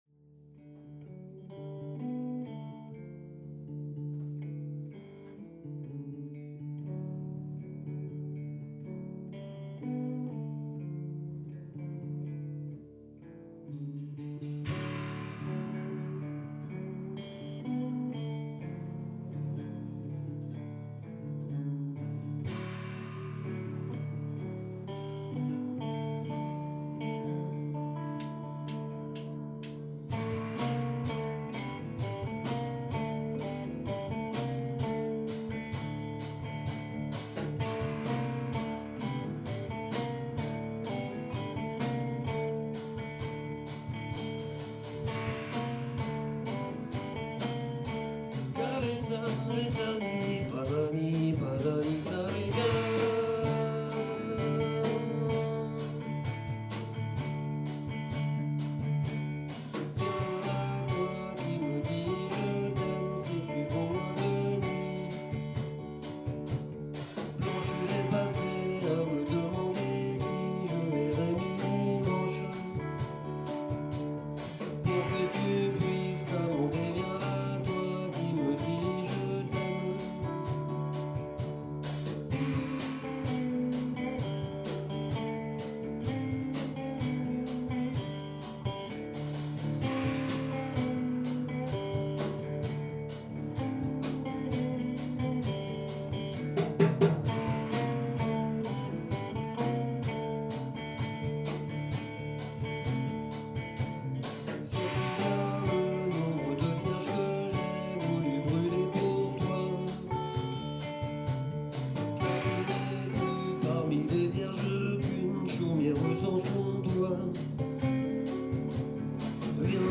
Recording small rock band with iPhone
The singer is the lead guitarist and the drummer has a minimal kit.
The Tascam was setup some mtrs away in the flat where they practice. Son says the attached mics were set for ' Crossed I pair'.